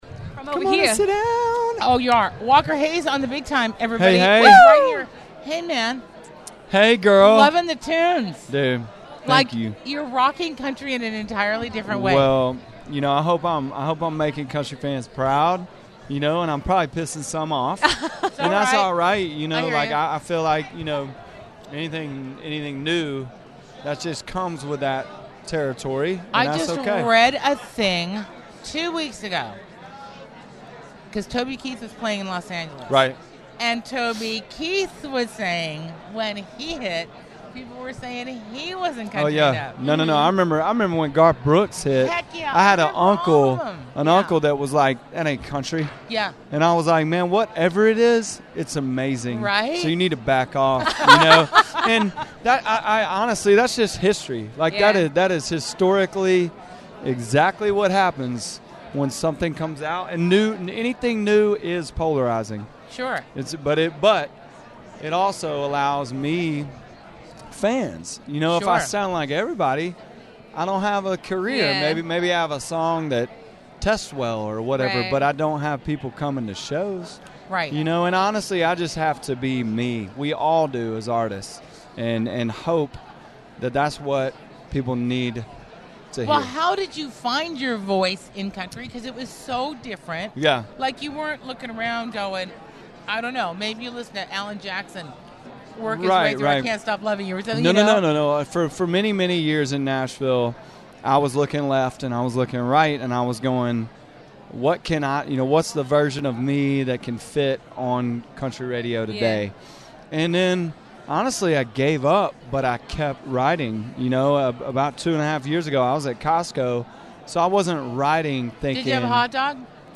Walker Hayes Interview At 2018 ACMs!